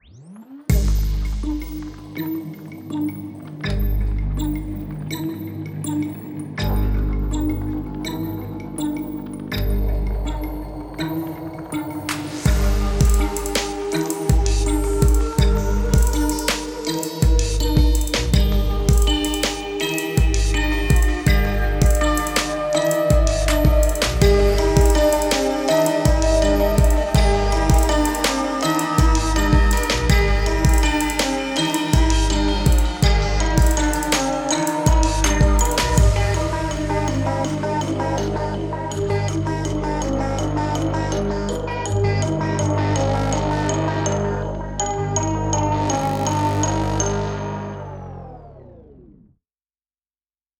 Unidentified textures and processed imperfection